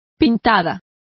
Also find out how pintada is pronounced correctly.